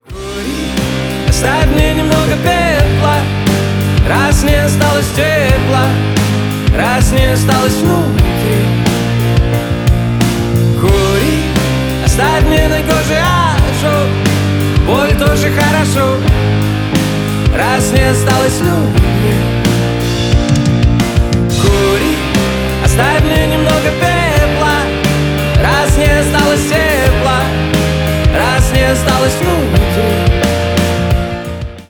Рок Металл # Поп Музыка